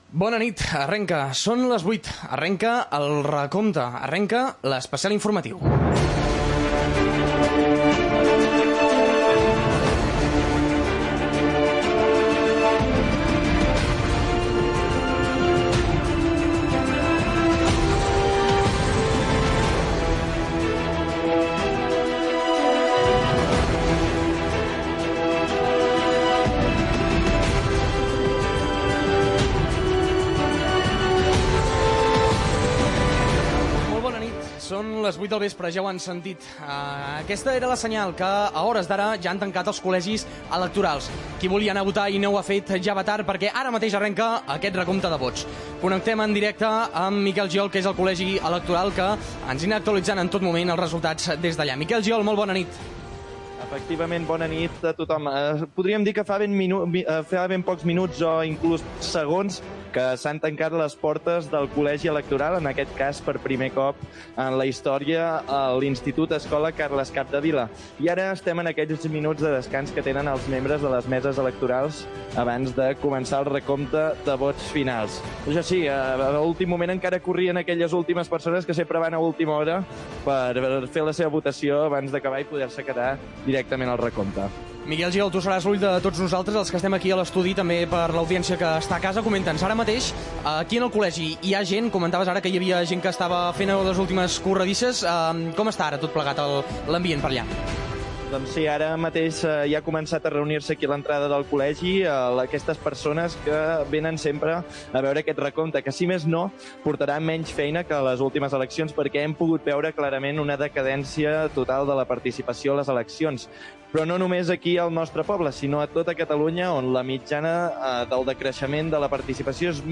connexió amb el col·legi electoral de Balenyà una vegada tancades les portes per a fer el recompte de vots
Gènere radiofònic Informatiu